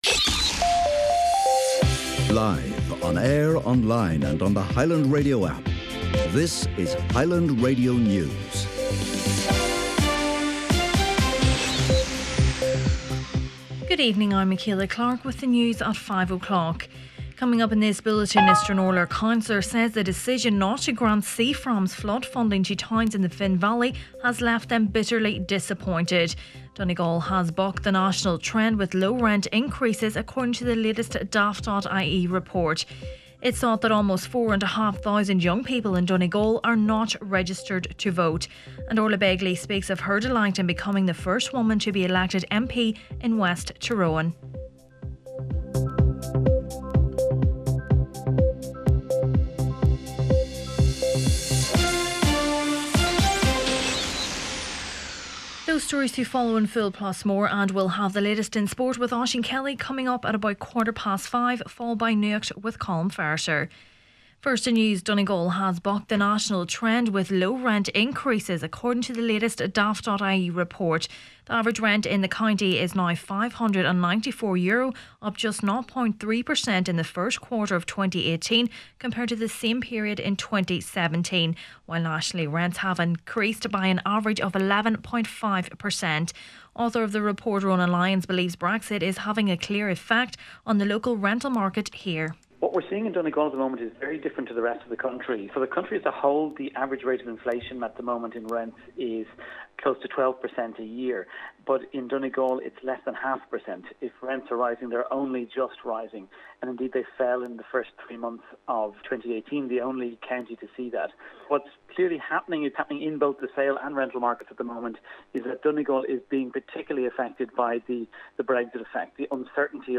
Main Evening News, Sport, Nuacht and Obituaries Friday 4th May